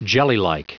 Prononciation du mot jellylike en anglais (fichier audio)
Prononciation du mot : jellylike